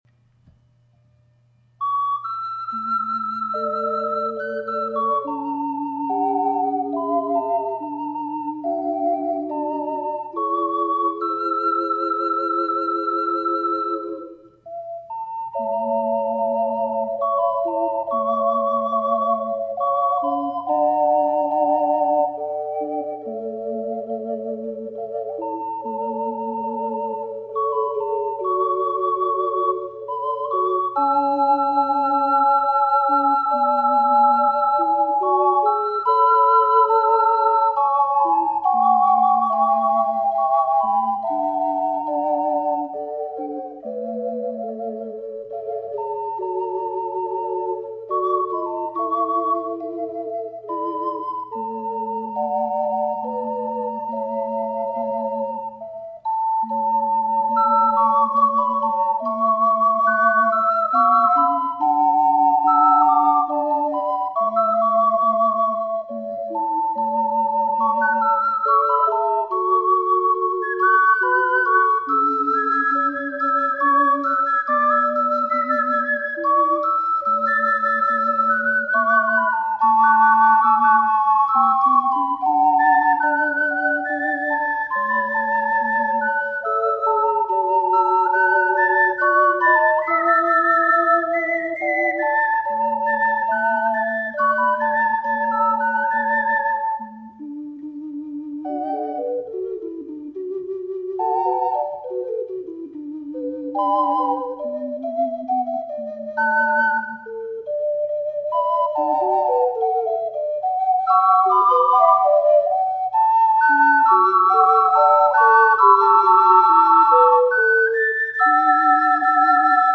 ④BC   楽譜より1音上げて吹きました。[ト長調（#1つ)→イ長調(#3つ）］